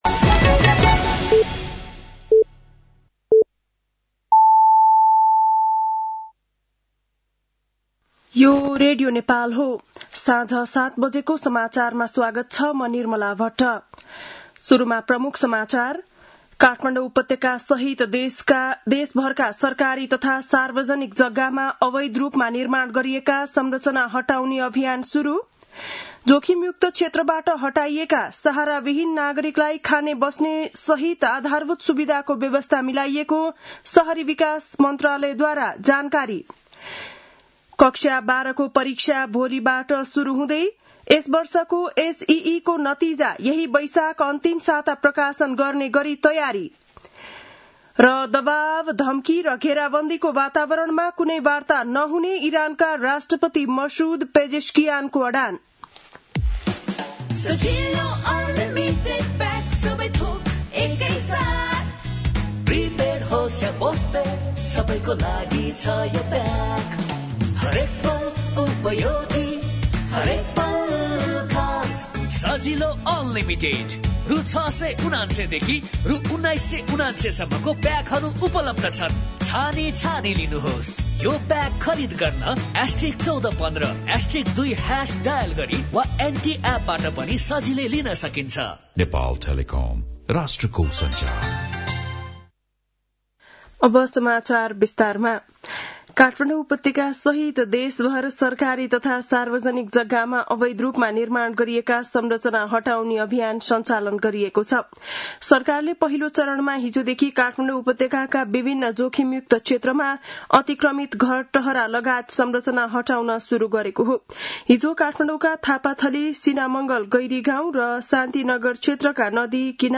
बेलुकी ७ बजेको नेपाली समाचार : १३ वैशाख , २०८३
7-PM-NEPALI-NEWS-1-13.mp3